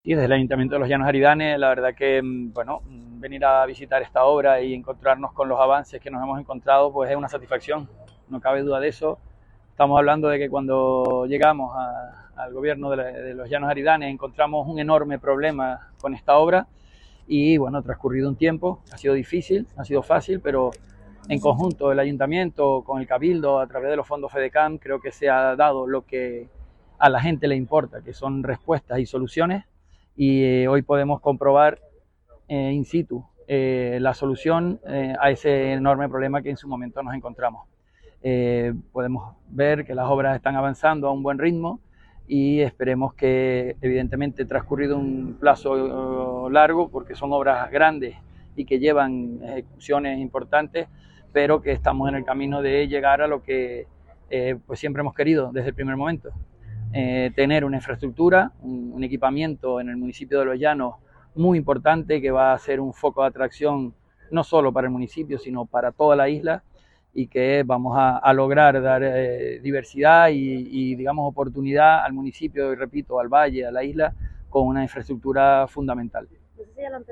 Declaraciones audio Manuel Perera PCIC.mp3